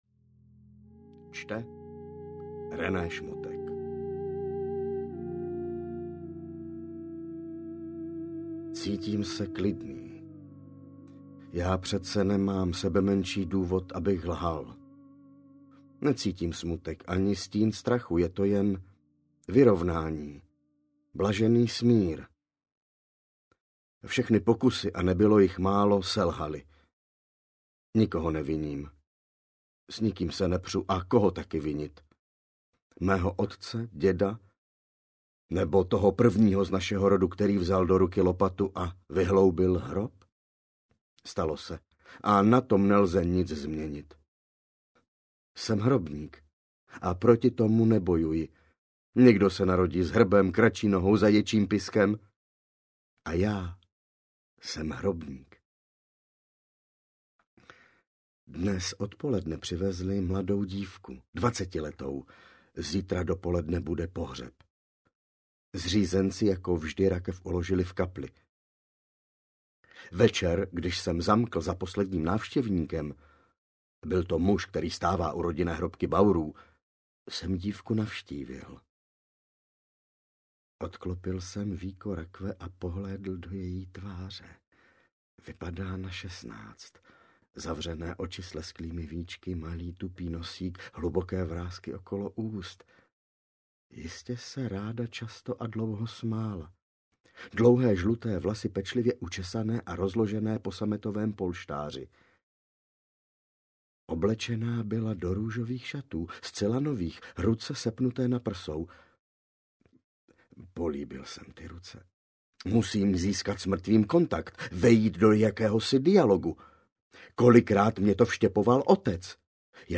Hrobník audiokniha
Ukázka z knihy